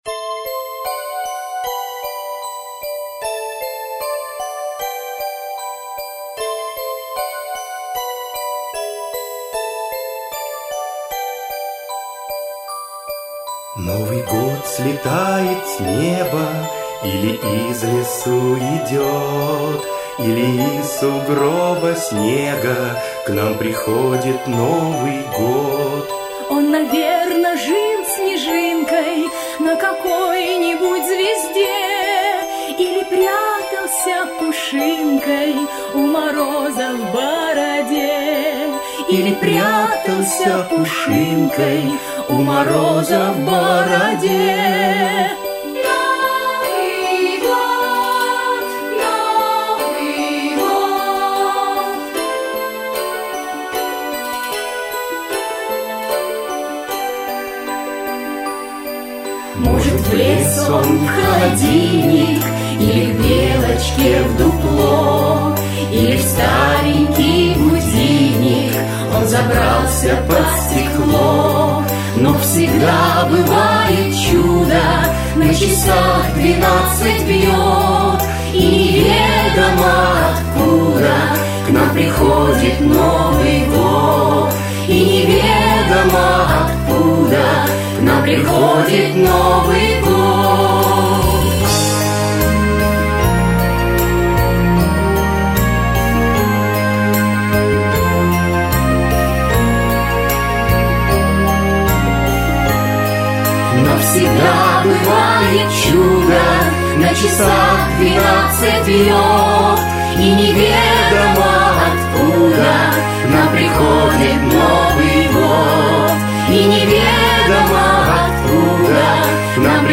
Аудиокнига Откуда приходит Новый год?
Автор Андрей Усачев Читает аудиокнигу Андрей Усачев.